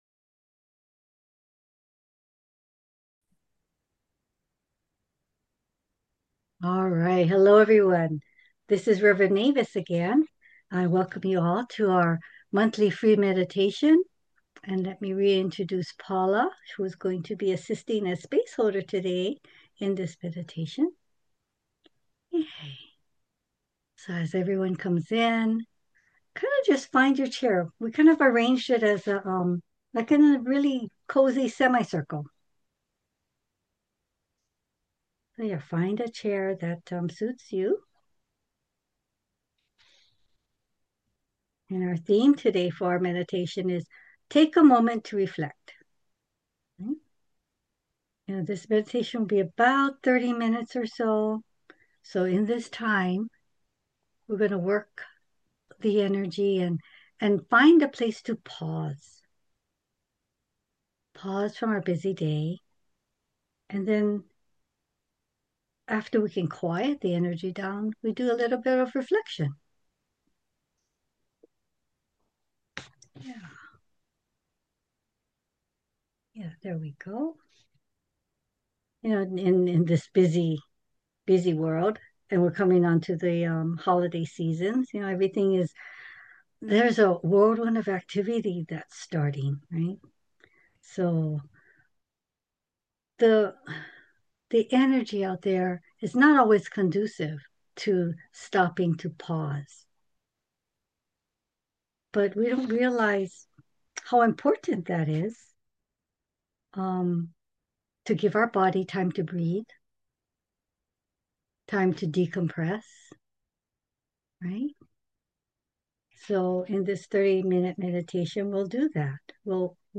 32 minutes Recorded Live on November 18